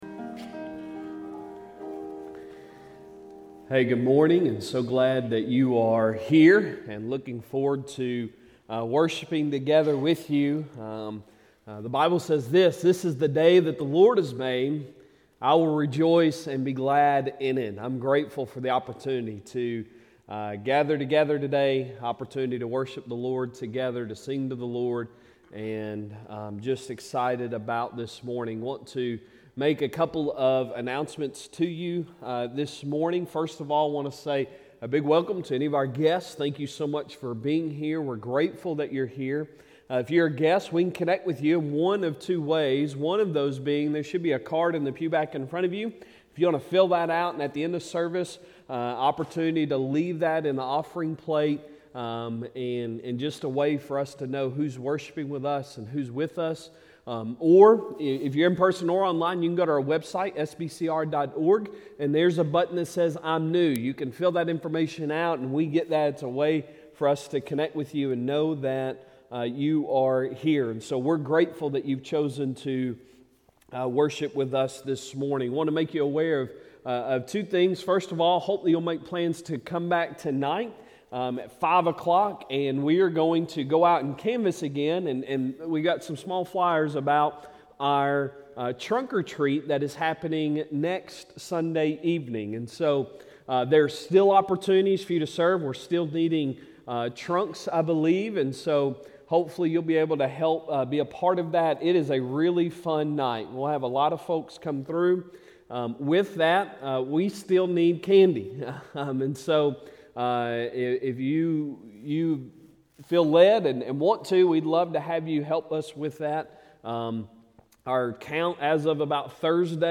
Sunday Sermon October 23, 2022